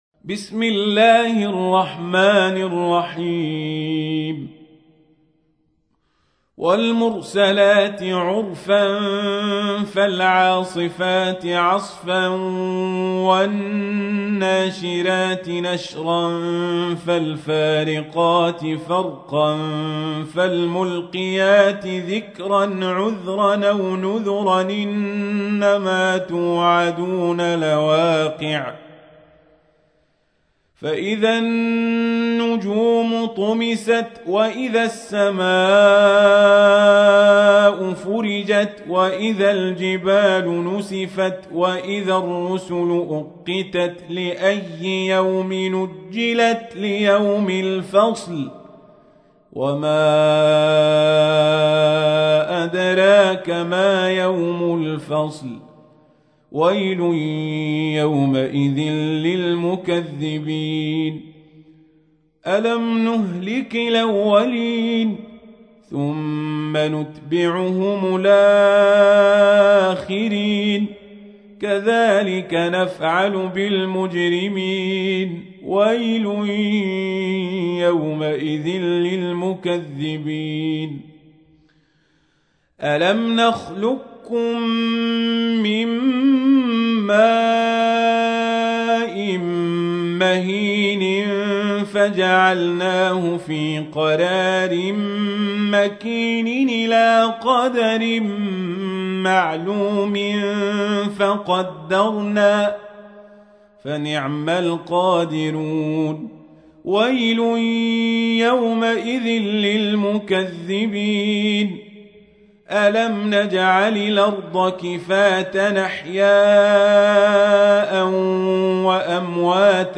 تحميل : 77. سورة المرسلات / القارئ القزابري / القرآن الكريم / موقع يا حسين